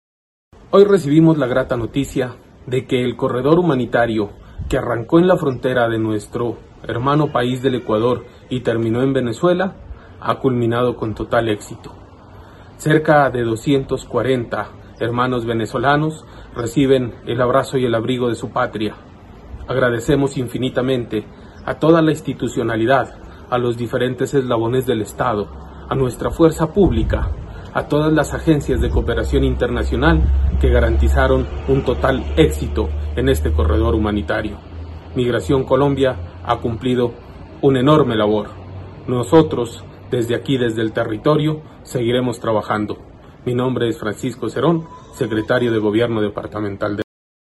Por su parte, el Secretario de Gobierno de Nariño Francisco Cerón (foto) señaló que: “Hoy recibimos la grata noticia que el corredor humanitario que arranco en la frontera con Ecuador terminó con total éxito en Venezuela. Cerca de 240 hermanos venezolanos reciben el abrazo y abrigo de su patria, agradecemos infinitamente a todas las instituciones que garantizaron el éxito de este paso humanitario”.
Regreso-de-venezolanos-Francisco-Cerón-1.mp3